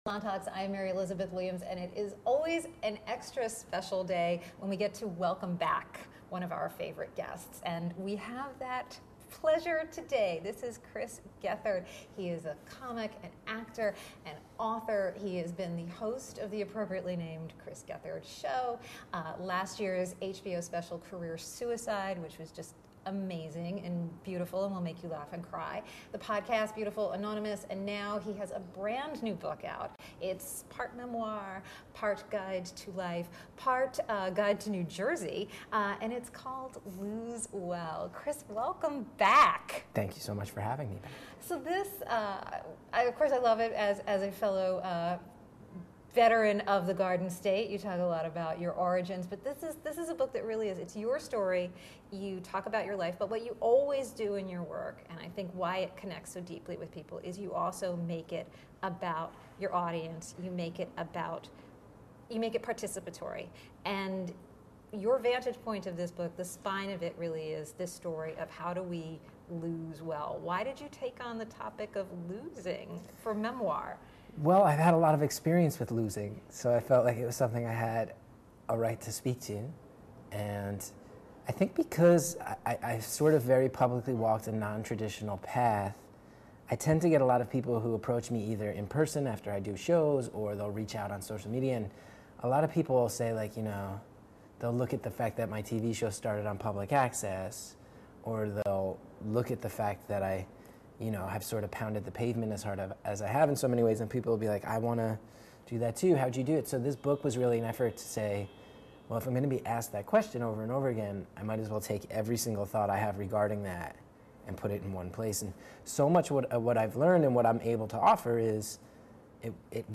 About “Salon Talks” Hosted by Salon journalists, “Salon Talks” episodes offer a fresh take on the long-form interview format, and a much-needed break from the partisan political talking heads that have come to dominate the genre.